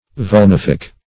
Search Result for " vulnific" : The Collaborative International Dictionary of English v.0.48: Vulnific \Vul*nif"ic\, Vulnifical \Vul*nif"ic*al\, a. [L. vulnificus; vulnus a wound + facere to make.] Causing wounds; inflicting wounds; wounding.
vulnific.mp3